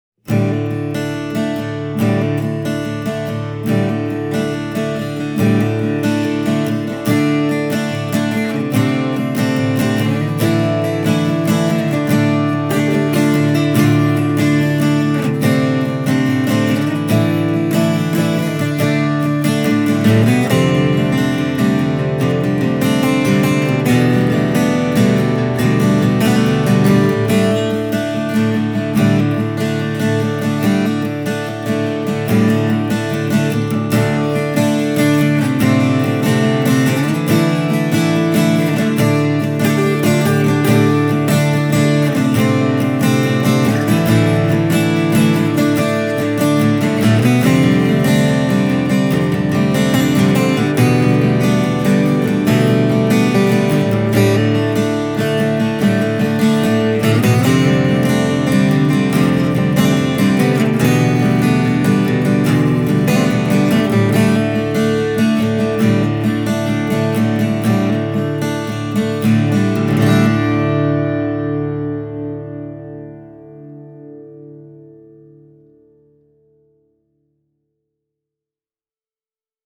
Testikitara soi täyteläisesti, mutta tarkasti, ja se tarjoaa aimon annoksen tuoreutta ja helinää diskantissa.
Demobiisi koostuu kolmesta stereoraidasta, joiden taltiointiin on käytetty kahta C3000-mikrofonia: